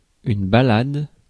Ääntäminen
Ääntäminen France: IPA: /ba.lad/ Haettu sana löytyi näillä lähdekielillä: ranska Käännös Ääninäyte Substantiivit 1. ballade 2. ballad US 3. saunter 4. junket US Suku: f .